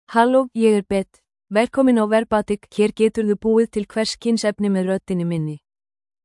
FemaleIcelandic (Iceland)
BethFemale Icelandic AI voice
Voice sample
Female
Beth delivers clear pronunciation with authentic Iceland Icelandic intonation, making your content sound professionally produced.